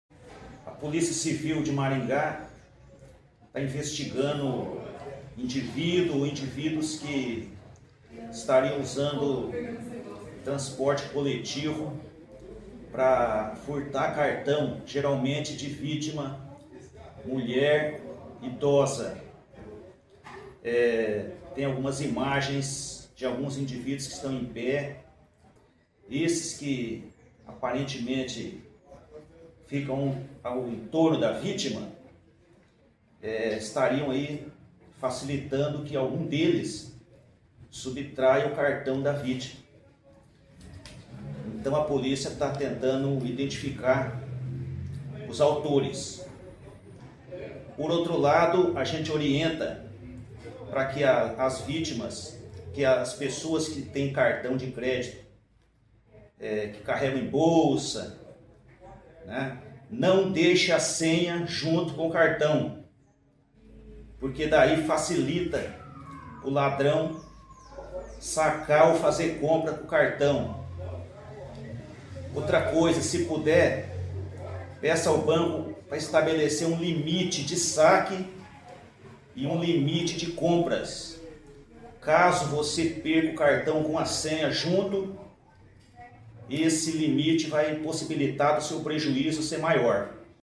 Ouça o que diz o delegado